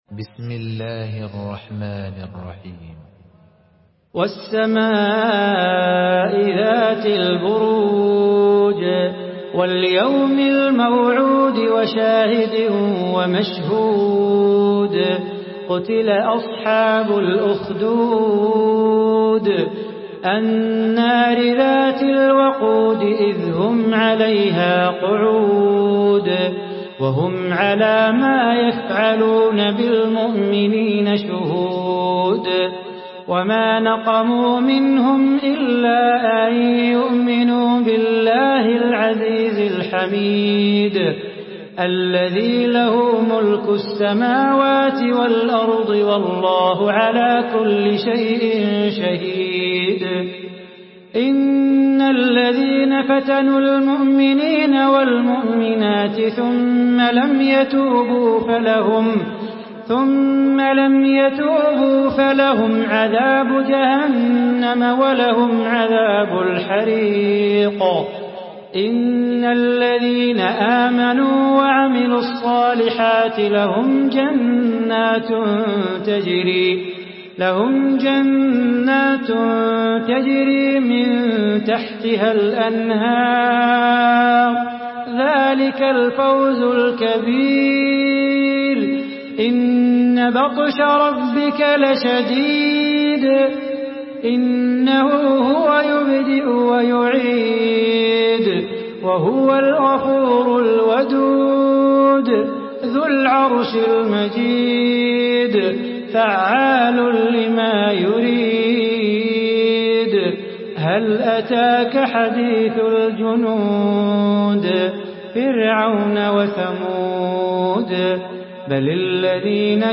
Surah আল-বুরূজ MP3 in the Voice of Salah Bukhatir in Hafs Narration
Murattal